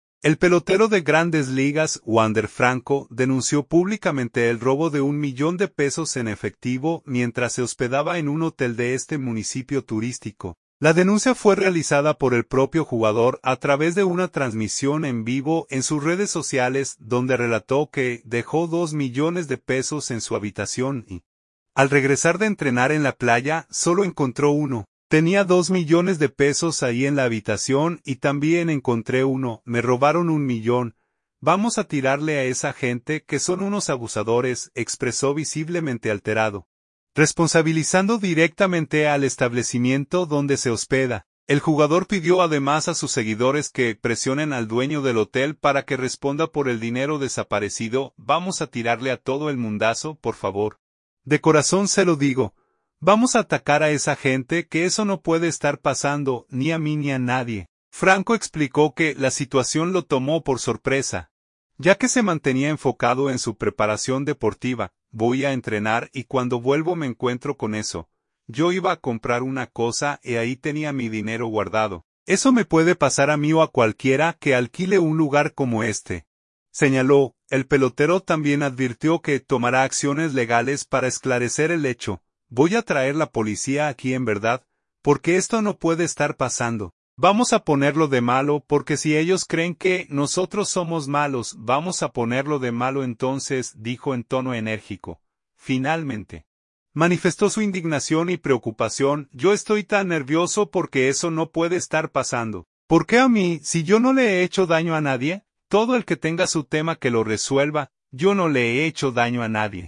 La denuncia fue realizada por el propio jugador a través de una transmisión en vivo en sus redes sociales, donde relató que dejó dos millones de pesos en su habitación y, al regresar de entrenar en la playa, solo encontró uno.
“Voy a traer la Policía aquí en verdad, porque esto no puede estar pasando. Vamos a ponerlo de malo, porque si ellos creen que nosotros somos malos, vamos a ponerlo de malo entonces”, dijo en tono enérgico.